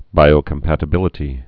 (bīō-kəm-pătə-bĭlĭ-tē)